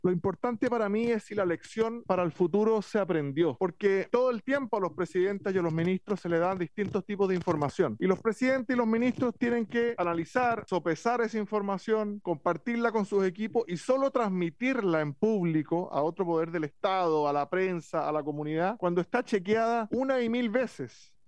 El UDI Jorge Alessandri, agradeció la participación de la ministra y le preguntó si había algún aprendizaje luego de este “impasse”
cuna-izkia-alessandri.mp3